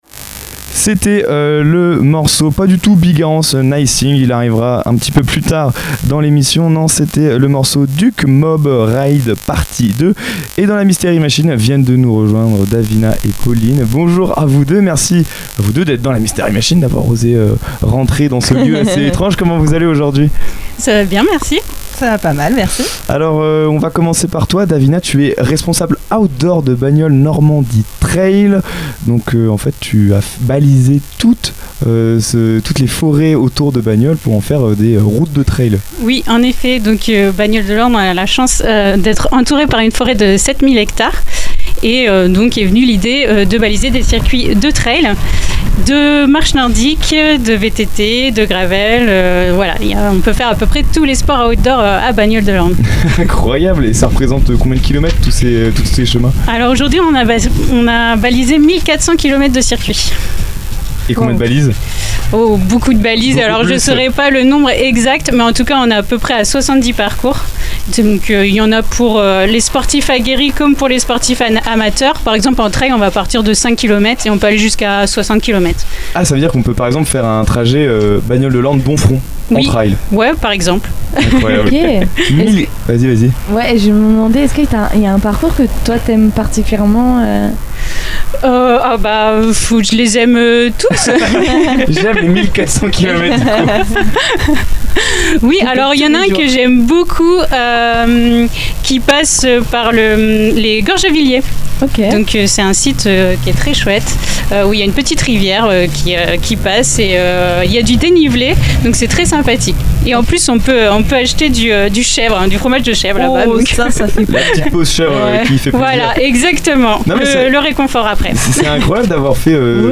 Mystery Machine Bagnole de l'Orne